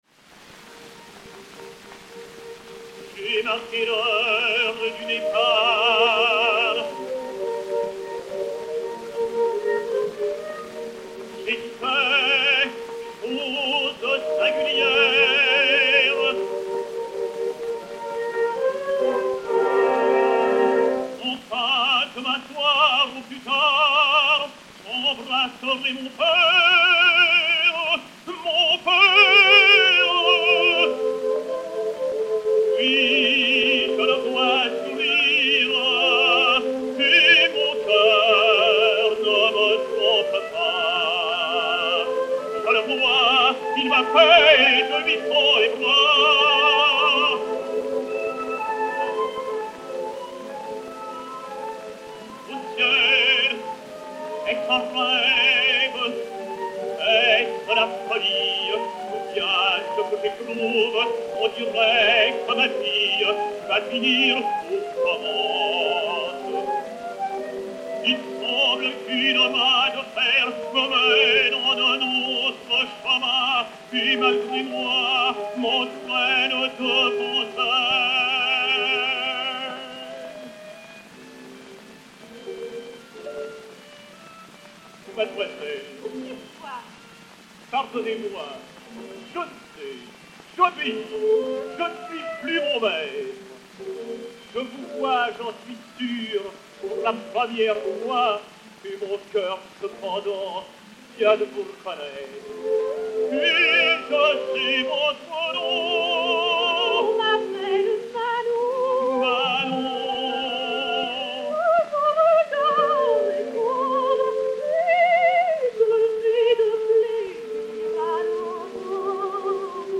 Lucette Korsoff (Manon), Léon Beyle (Des Grieux) et Orchestre